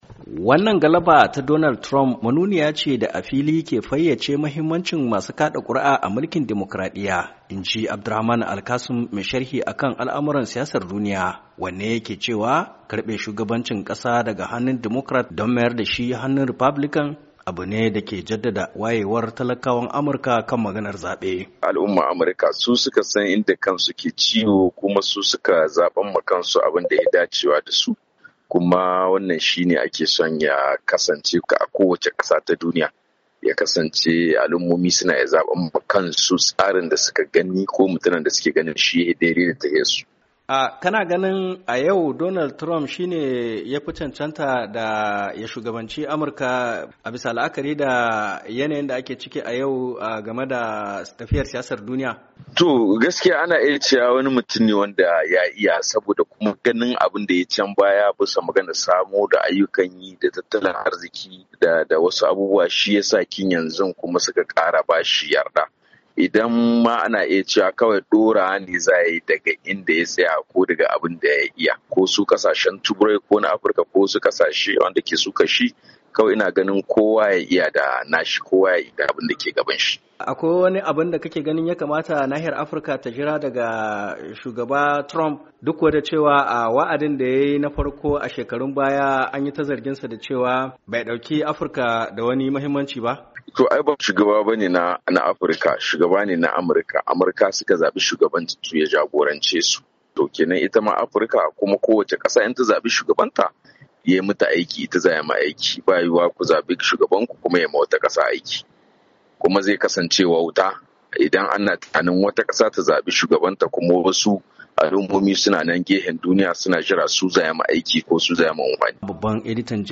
NIAMEY, NIGER —